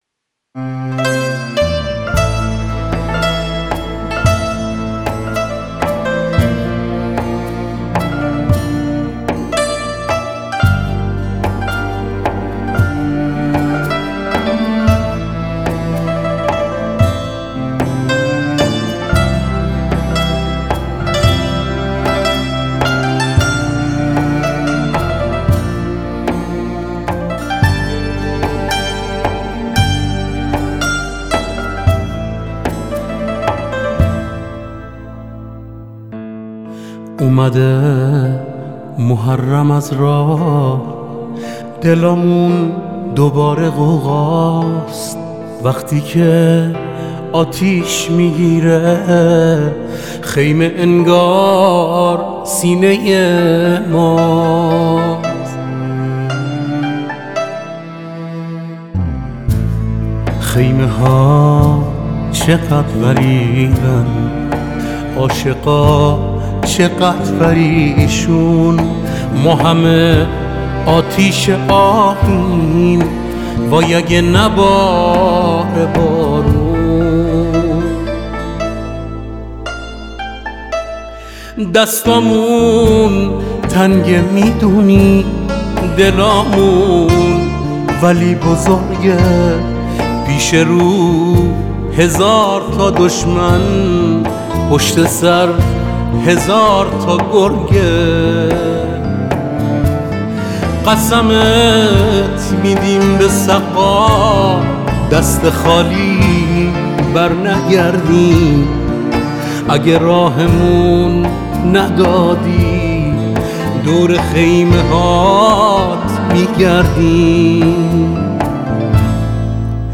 خواننده موسیقی پاپ